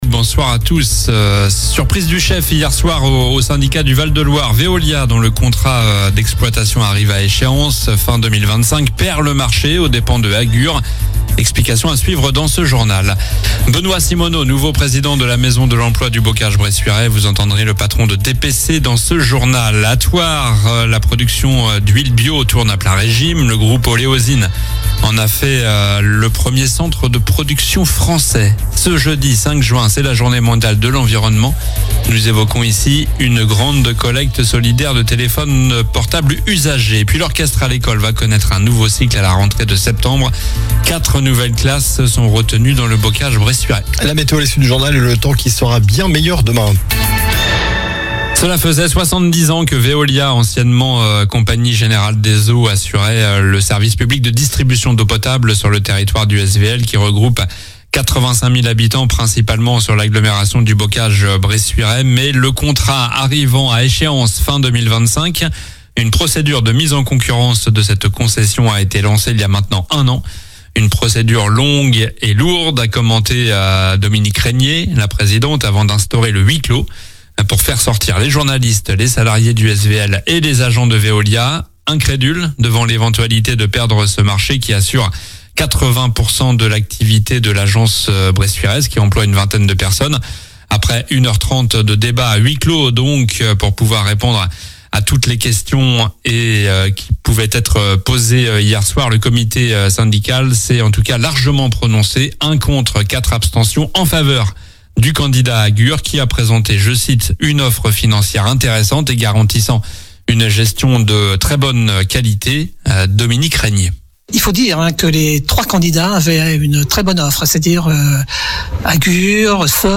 Journal du jeudi 05 juin (soir)